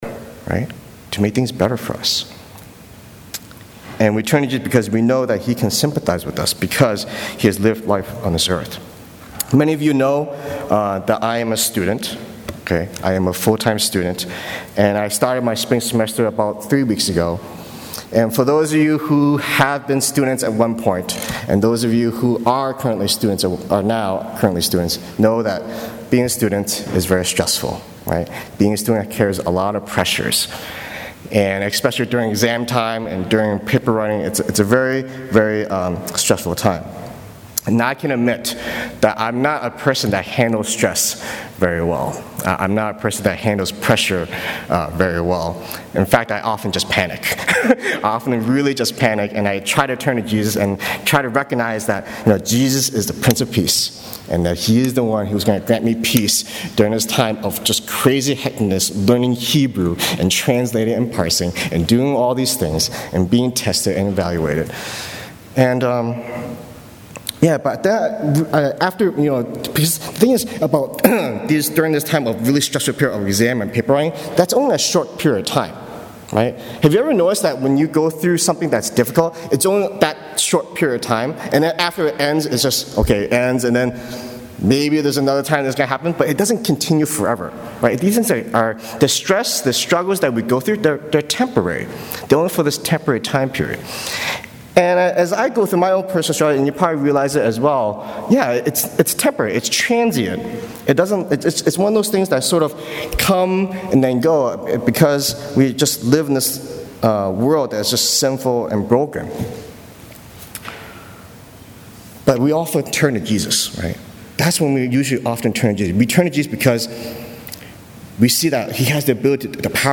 Tag: Recent Sermons - Page 87 of 178 | Boston Chinese Evangelical Church